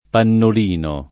pannolino [ pannol & no ] s. m.